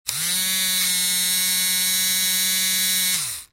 Компактный миксер на батарейках